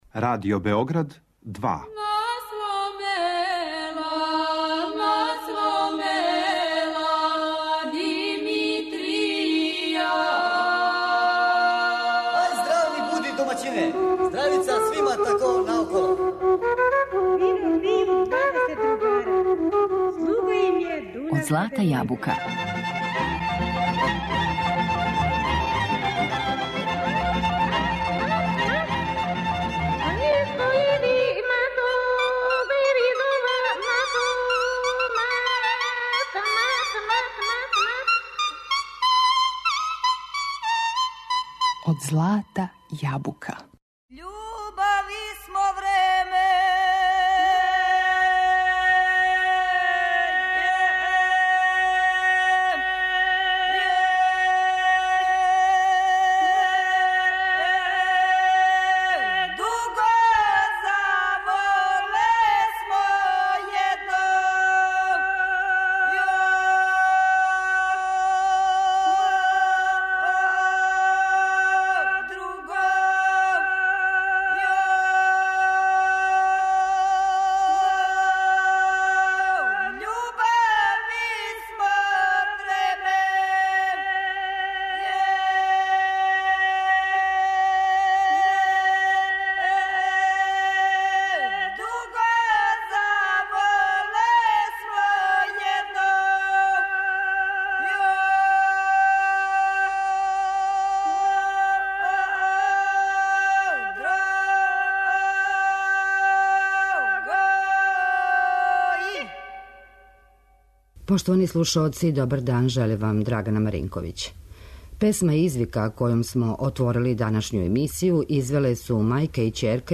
Пред нама су снимци начињени у периоду од 1971. до 1993. године и представљају праве бисере наше вокалне музичке традиције.